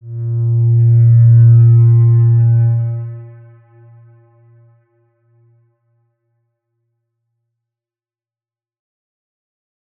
X_Windwistle-A#1-pp.wav